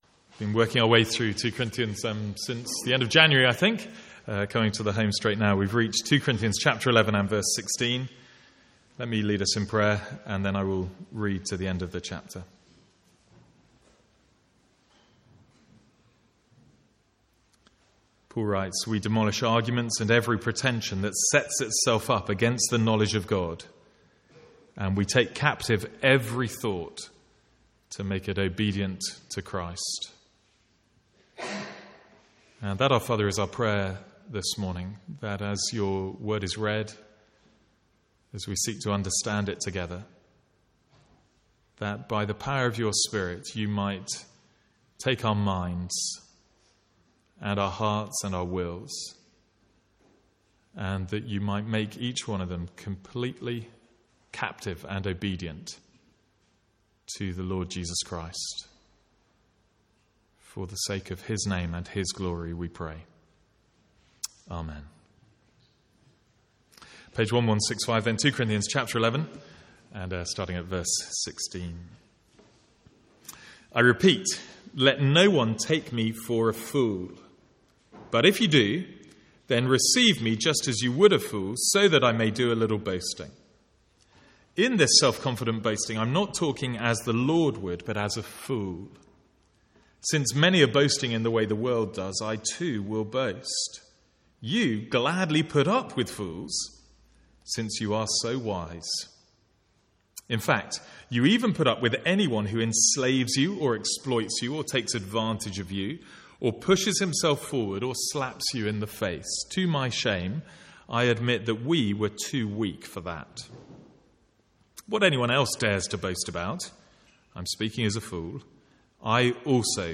Sermons | St Andrews Free Church
From the Sunday morning series in 2 Corinthians.